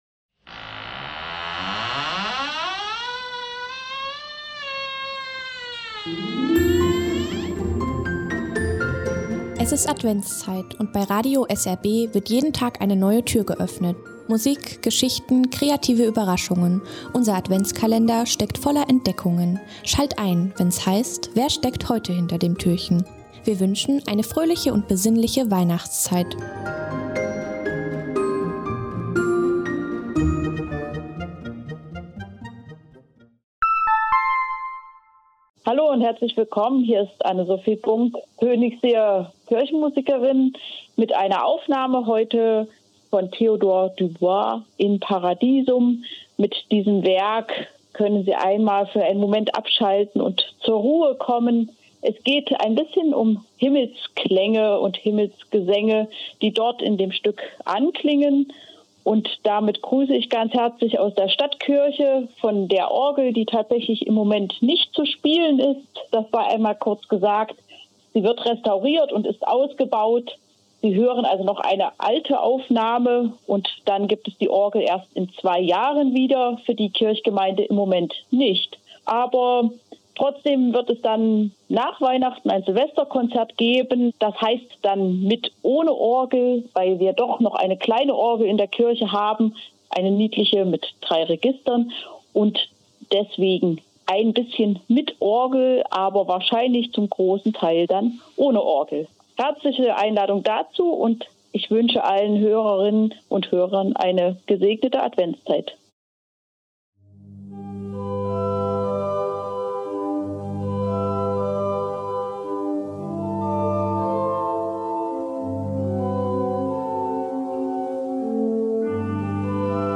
Kantorin